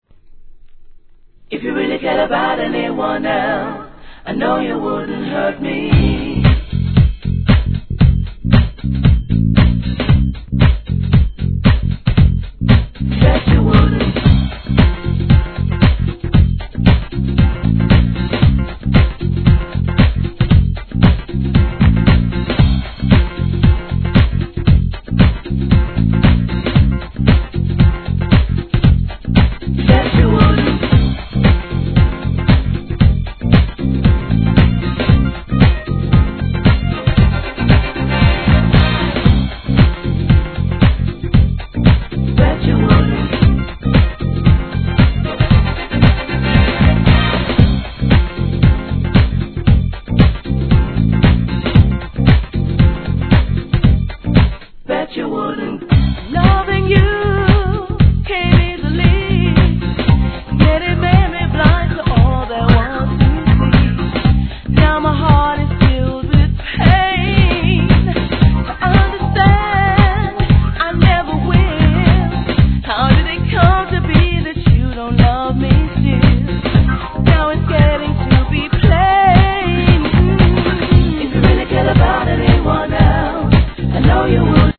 1. HIP HOP/R&B
CLASSIC GARAGE MIX